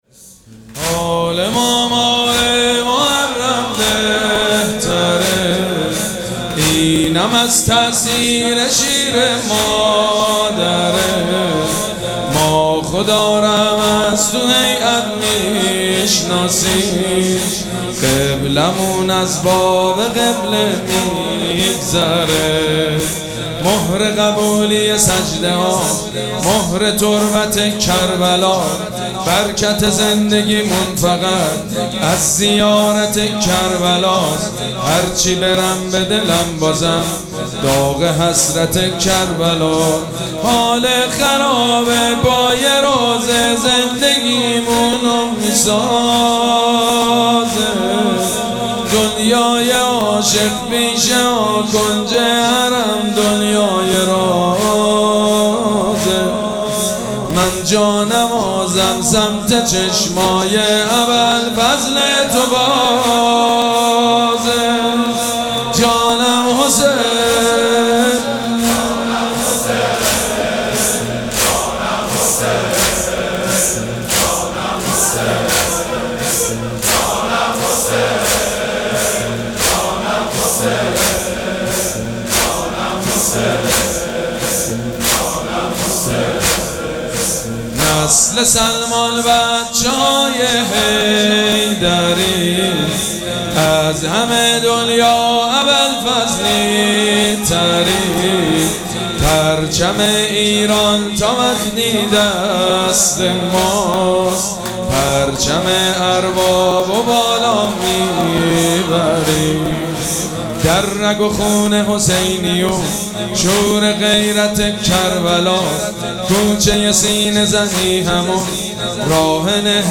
مراسم عزاداری شب نهم محرم الحرام ۱۴۴۷
حاج سید مجید بنی فاطمه